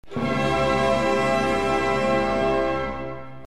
2. Orquestração de acordes - Tutti.
2.1.1. Com dinâmica forte.
2.1.1.1. Acordes perfeitos maiores.
Gewandhausorchester Leipzig, Kurt Masur
O tímpano toca a fundamental.